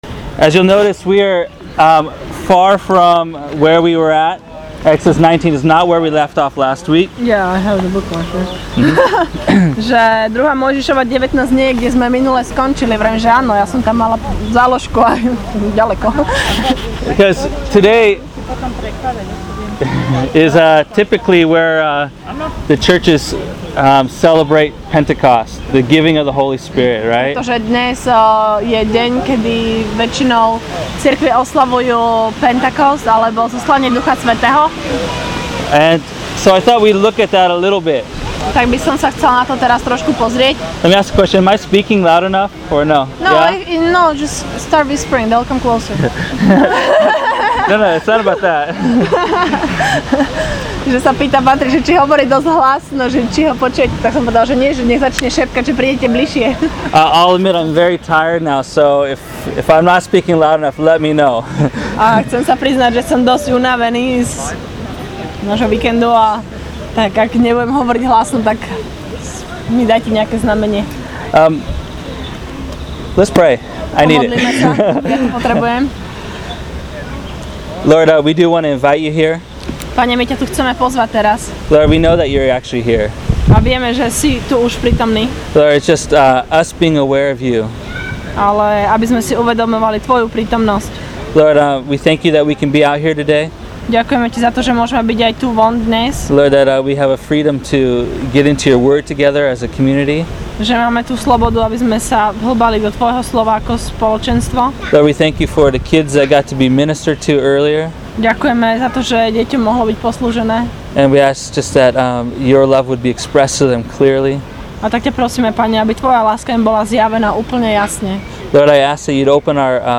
Enjoy this teaching on the festival of Shavout & Pentecost taken from Exodus 19 & Acts 2.